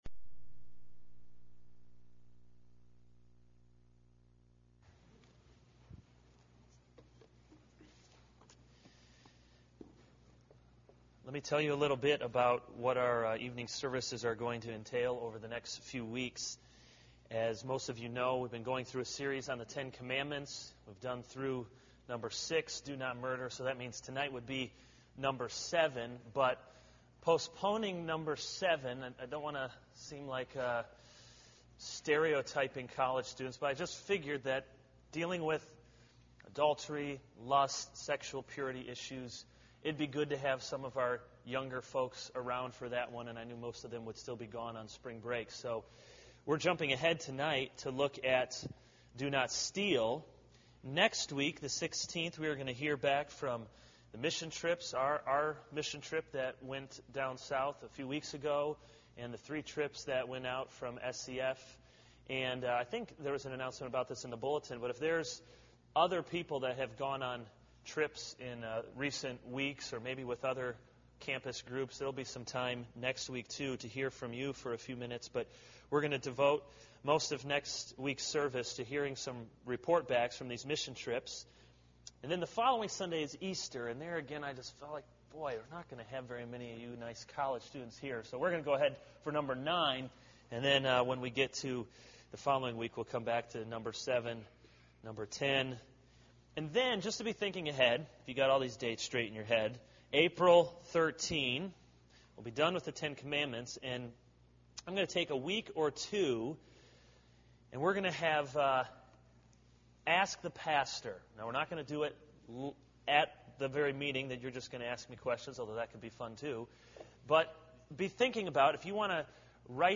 This is a sermon on Exodus 20:1-17 - Do not steal.